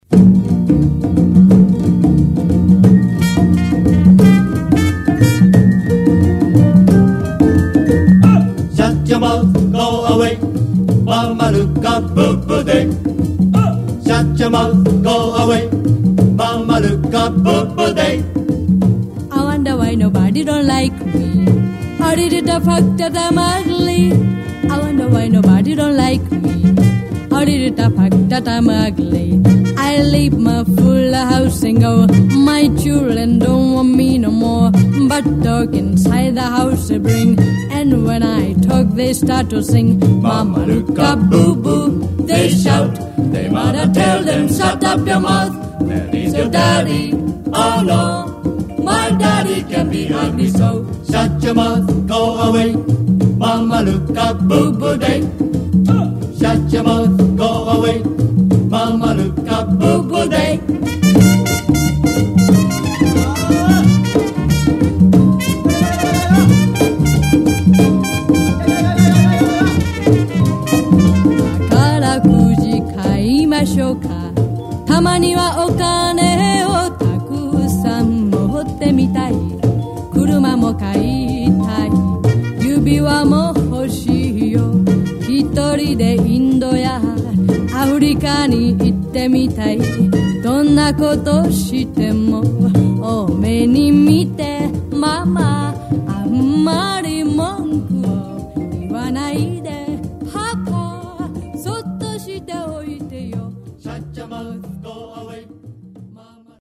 日本におけるカリプソ受容史の中でも、特筆すべき金字塔といえる一曲。
南国のリズムと昭和のユーモアがひとつに溶け合い、